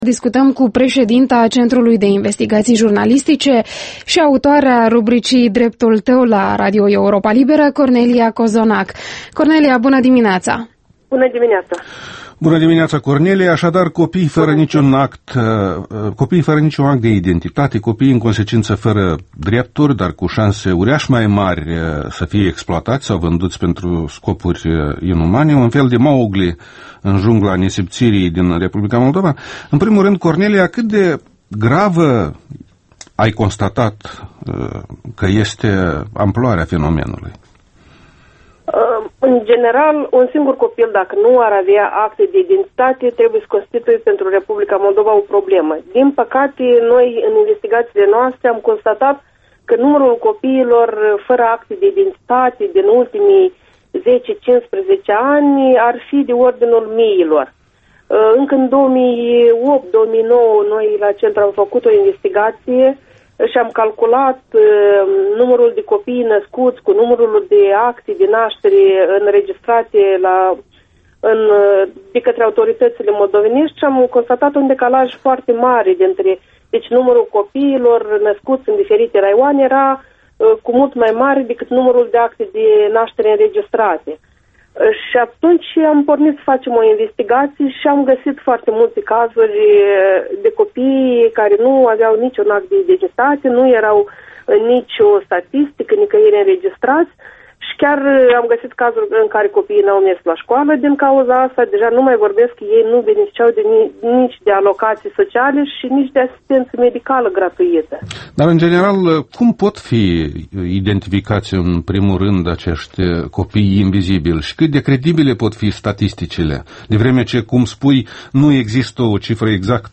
Interviul dimineții la Europa Liberă